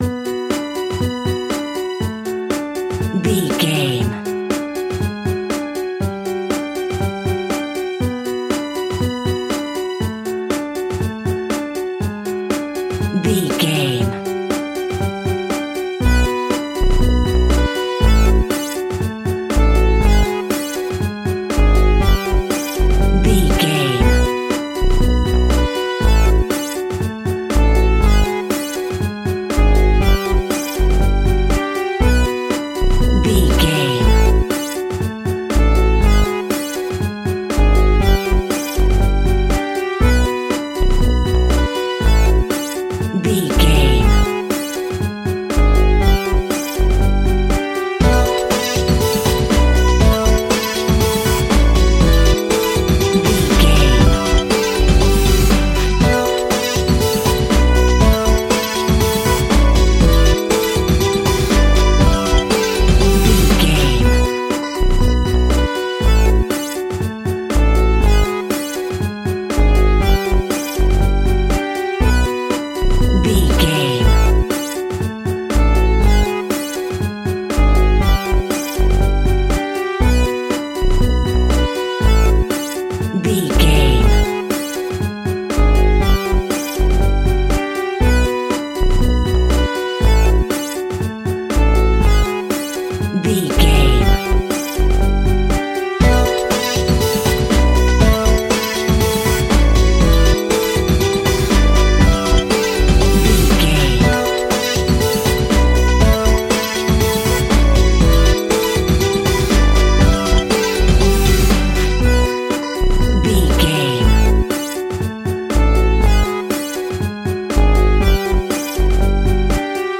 In-crescendo
Aeolian/Minor
Funk
hip hop
turntables
electronic
drum machine
synths